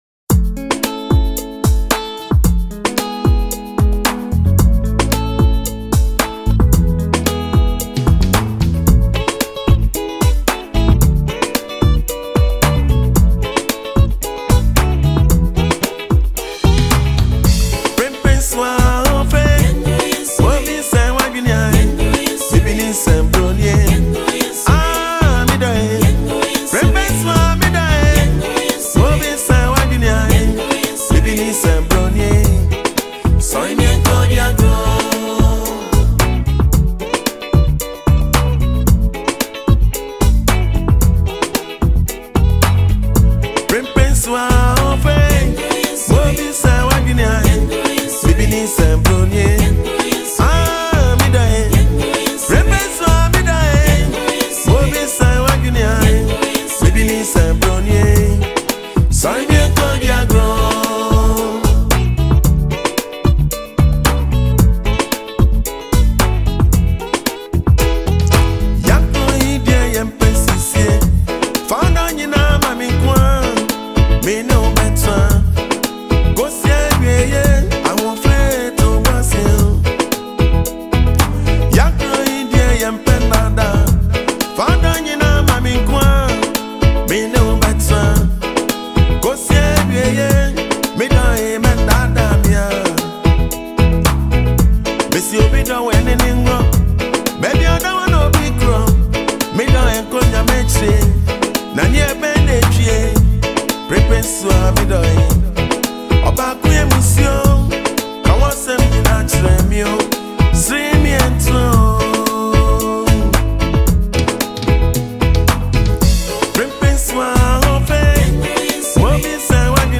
smooth, rhythmic, and melodious highlife tune
warm vocals
• A true Ghanaian highlife classic that never fades.
• Smooth vocals and heartfelt lyrics from a musical legend.